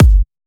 VDE 128BPM Renegade Kick.wav